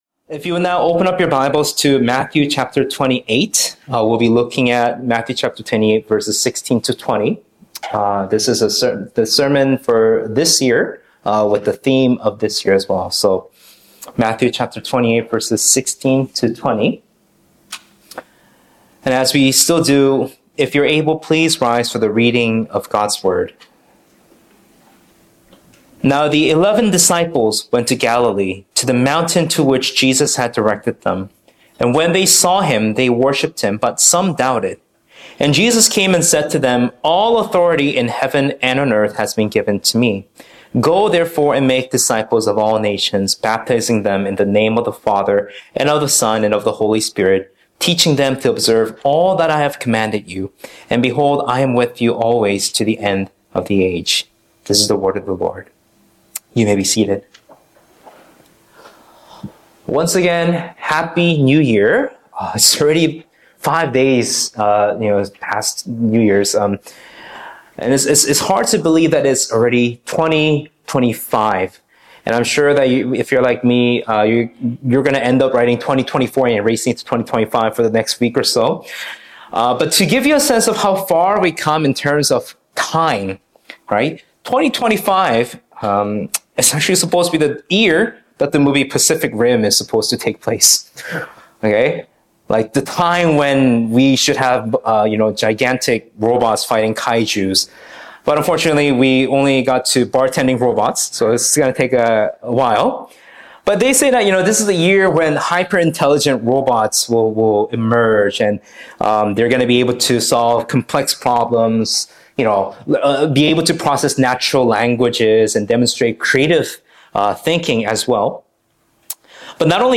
Sermons | Sonflower Community Church